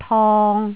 韻母 ip, it 到頁頂
ip 摺　疊　業
it 結　熱　烈
與〔im〕和〔in〕一樣，如果你把上列字例拉長來讀，便會聽到韻母〔i〕夾在中間。